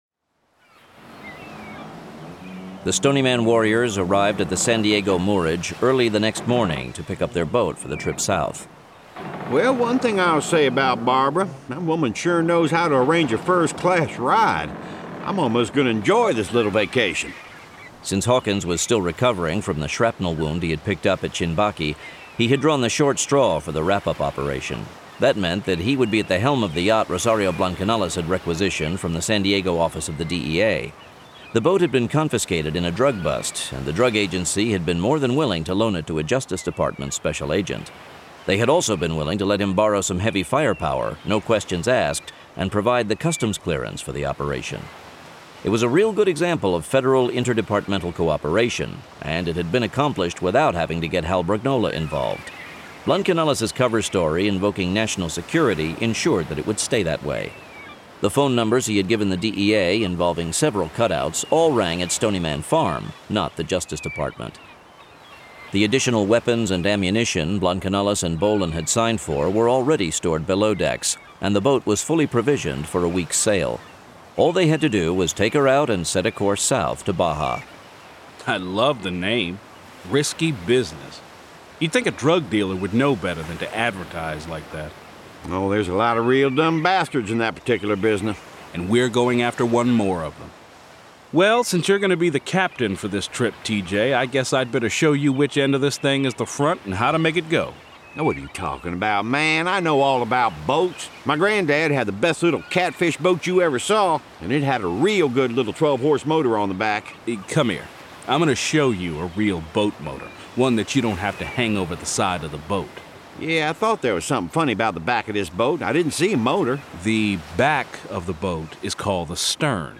This is an actively updated collection of graphic audio material.